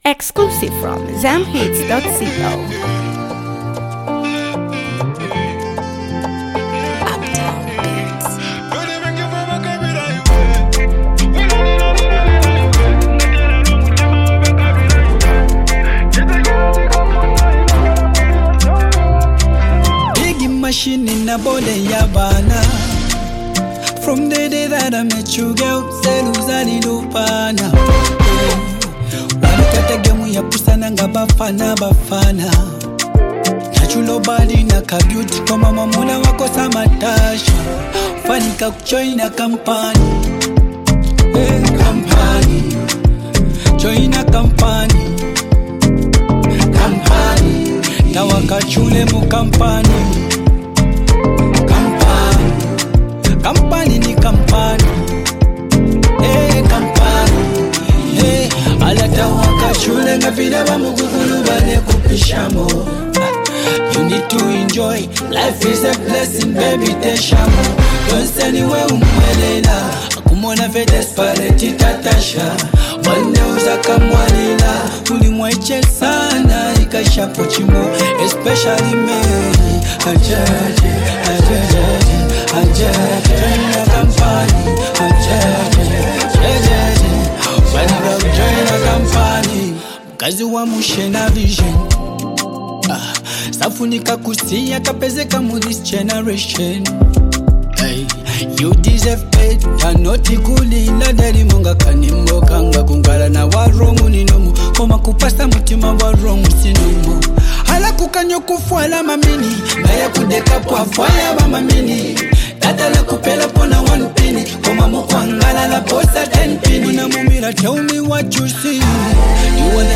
known for her soulful vocals and emotional delivery
upbeat, danceable vibe